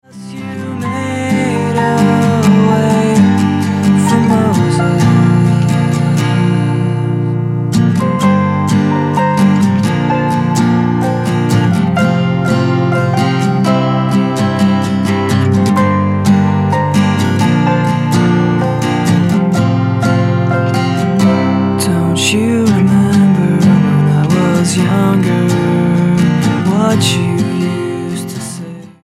STYLE: Rock
abrasive, post punk, emo rock